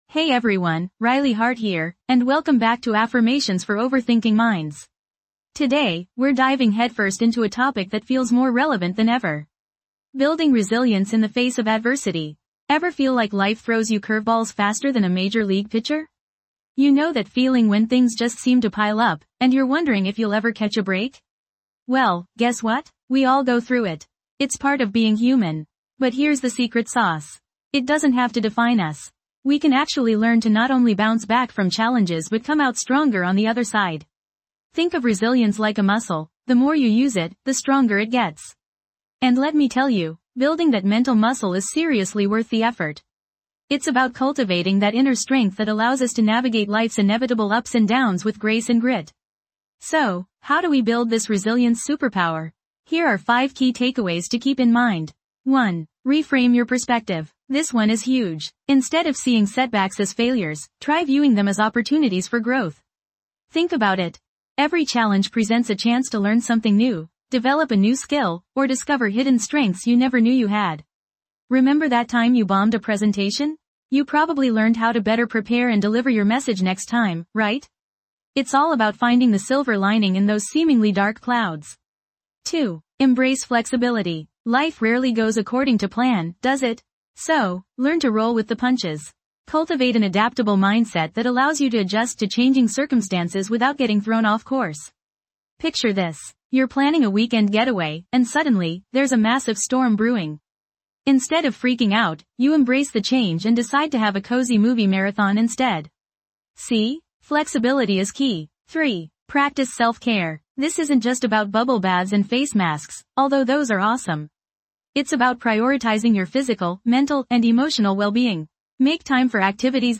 Through powerful affirmations, guided meditations, and soothing soundscapes, this podcast helps you break free from the cycle of overthinking and rediscover mental clarity. Whether you struggle with worry, anxiety, or intrusive thoughts, join us as we explore practical techniques to calm your mind and cultivate a more peaceful inner world.